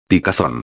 03/07/2011 Picazón Coceira •\ [pi·ca·zón] \• •\ Substantivo \• •\ Feminino \• Significado: Sensação incômoda na pele que provoca vontade de coçar.
picazon.mp3